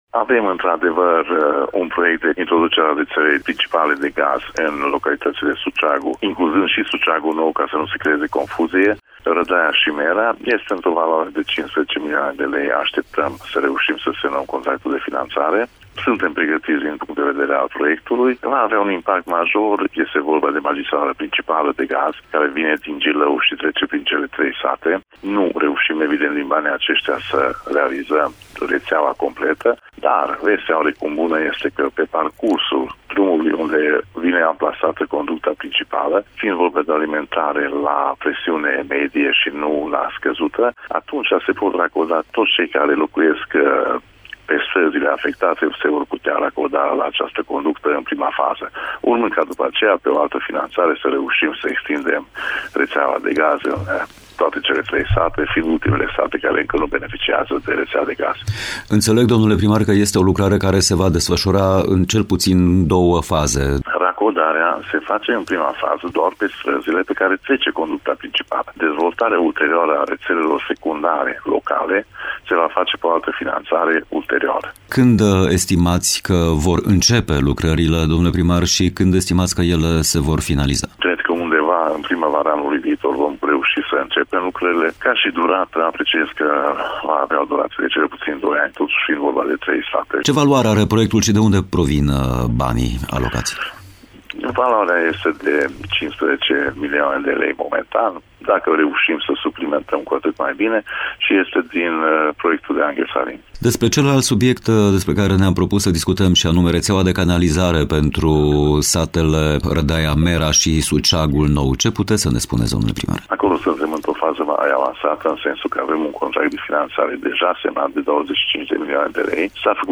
Despre lucrările de introducere a gazului și a rețelei de canalizare aflăm detalii de la primarul comunei Baciu, Balázs János: